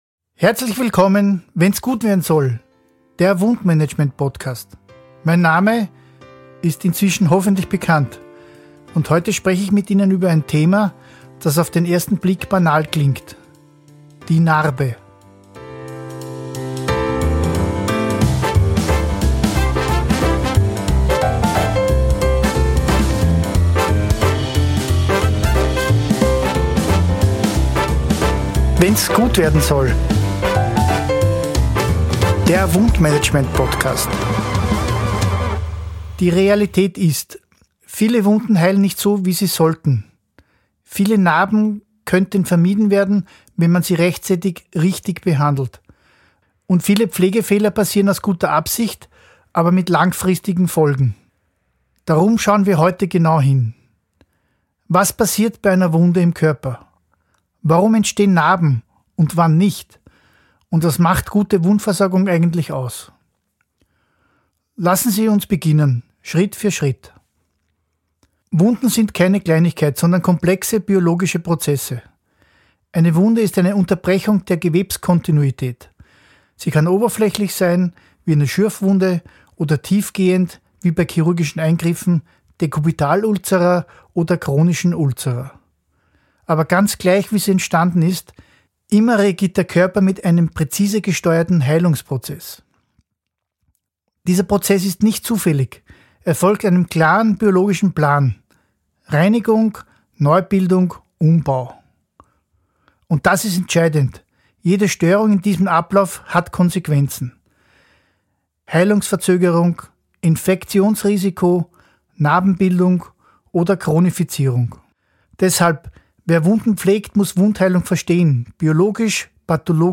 Sprecher
Bearbeitung & Sounddesign